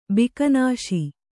♪ bikanāśi